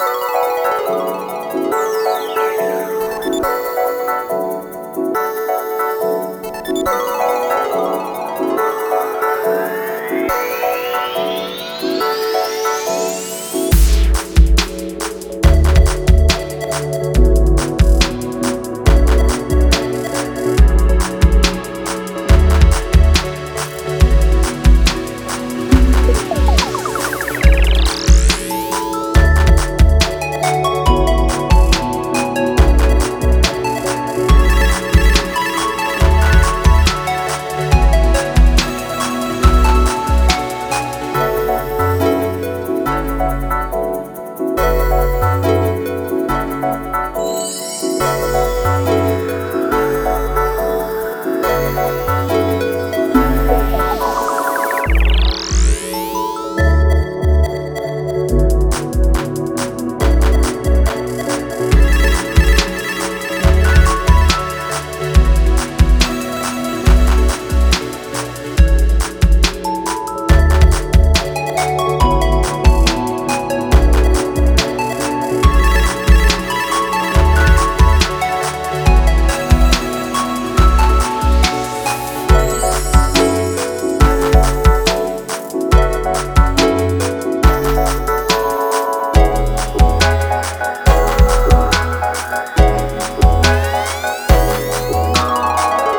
◆ジャンル：Future/フューチャー